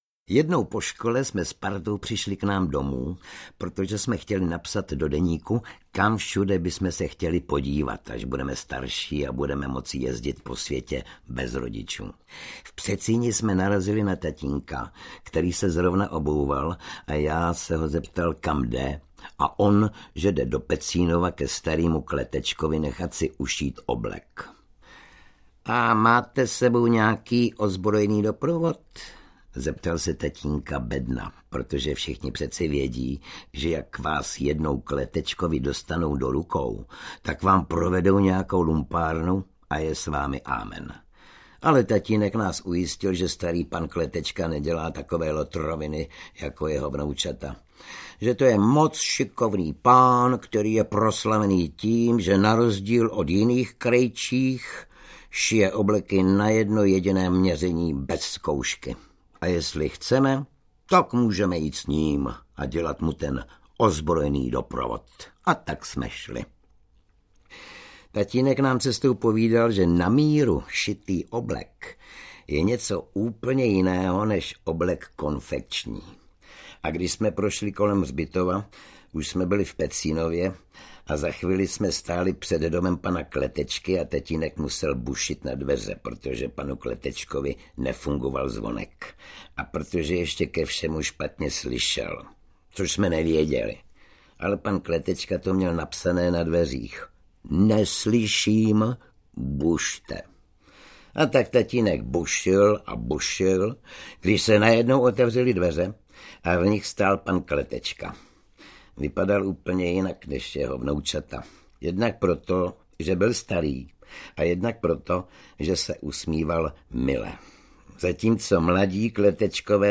Audiokniha Průšvihy Billa Madlafouska - obsahuje další příhody z úspěšné knížky pro děti.
Ukázka z knihy